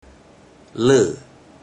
Click each Romanised Teochew word or phrase to listen to how the Teochew word or phrase is pronounced.
ler4